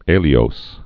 (ālē-ōs, älē-ōs)